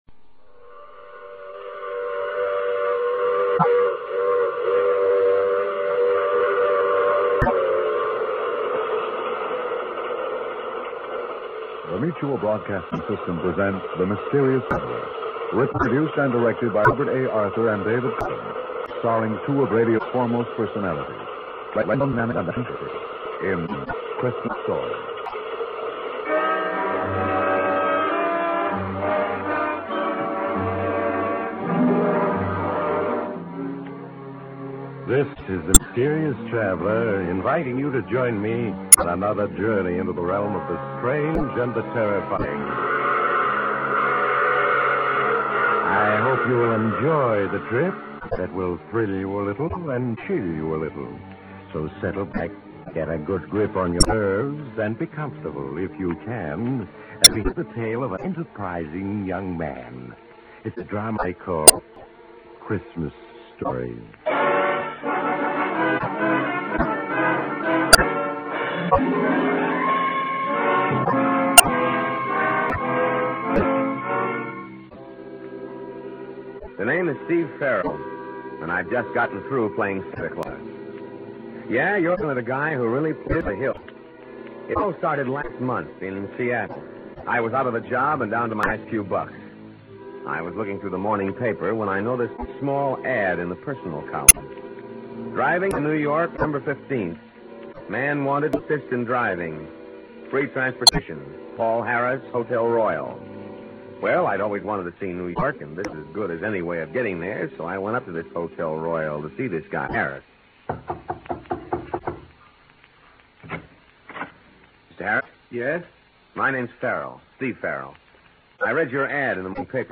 Transport yourself back to the golden age of radio with these enchanting old-time Christmas radio shows.